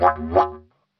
迪吉里杜管10
用铁三角Pro 70迷你话筒录制。 17年的巨型木制竹迪吉里杜管（由我制作）。
标签： 90 bpm Ethnic Loops Didgeridoo Loops 172.23 KB wav Key : Unknown